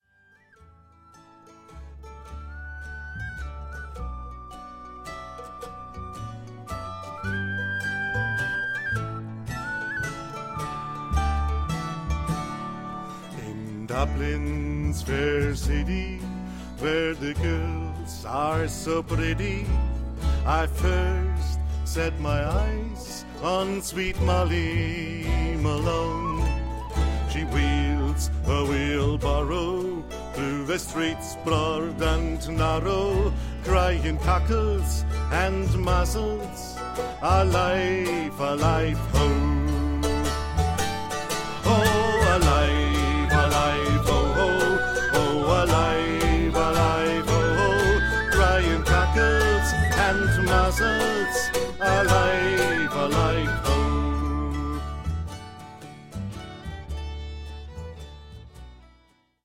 Irish folk – Back to Ireland